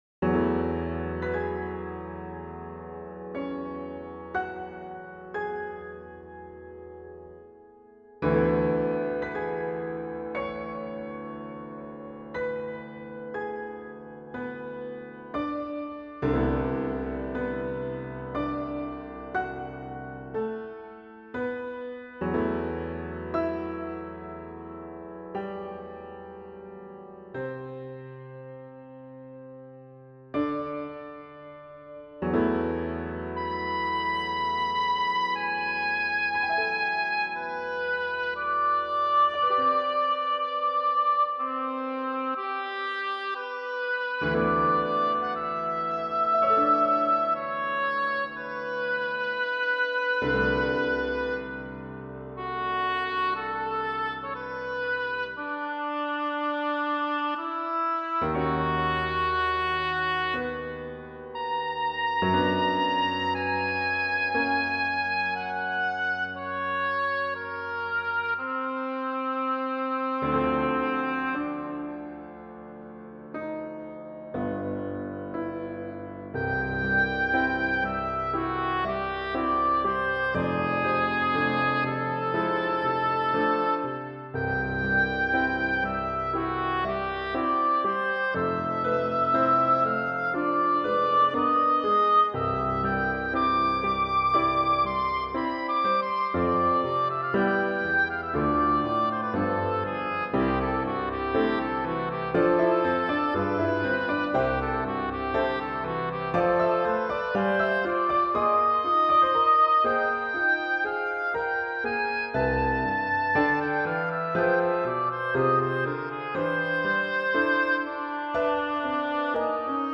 Music for Woodwinds and Piano
Oboe Sonata 1.mp3